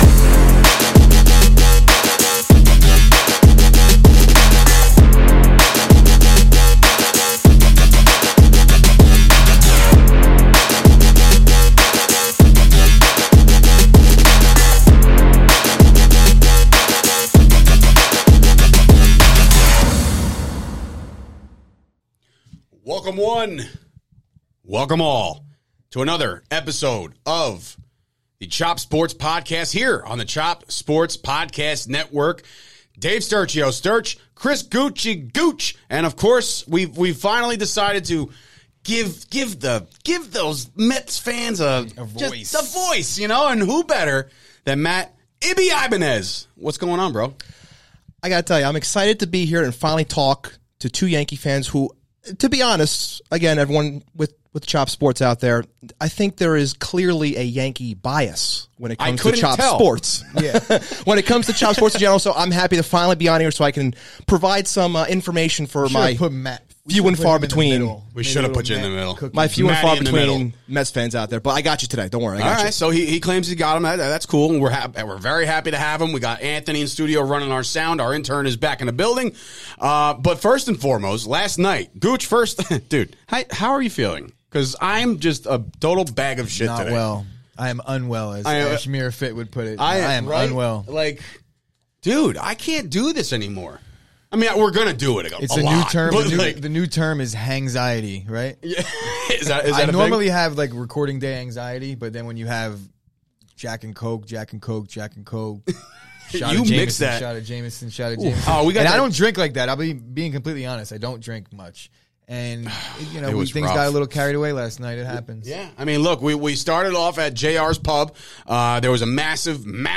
in studio to talk all things NFL Draft trades and compare the 2021 Yankees and Mets heading into opening week!